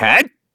Kibera-Vox_Attack3_kr.wav